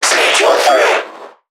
NPC_Creatures_Vocalisations_Infected [83].wav